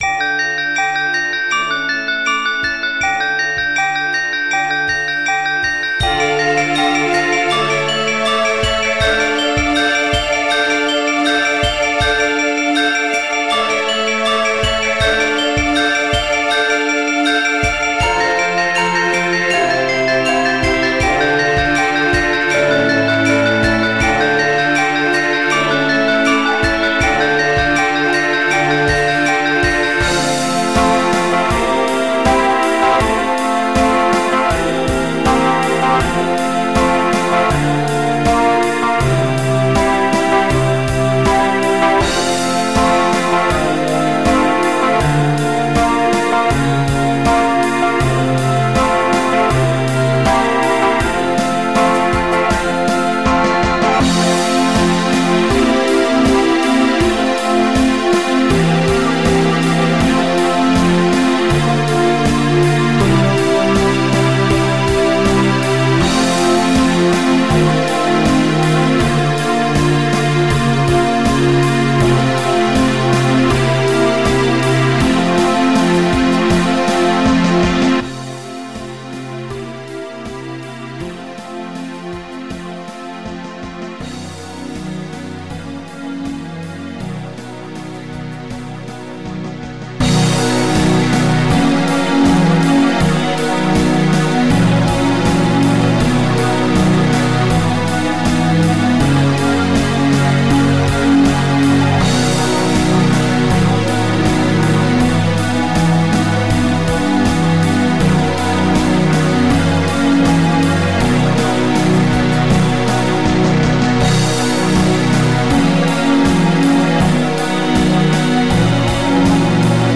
（歌なし）